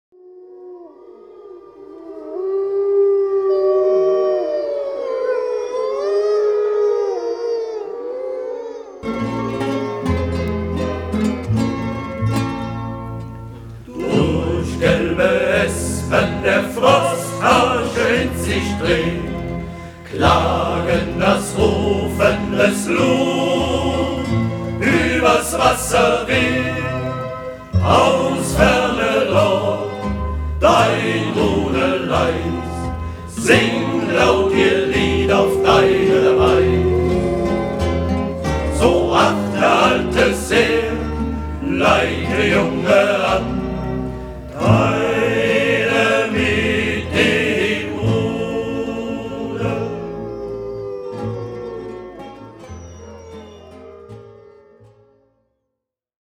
aus: Der Raschlerden Grauen frei -> Dichterisch für ein Rudel von Grauwölfen.-> Heulendes Wolfsrudel [4.446 KB] - mp3-> Lied: Der Wölfe Gesetz mit Wolfsgeheul [2.196 KB] - mp3-> Wölfe
mp3derwoelfegesetzmix.mp3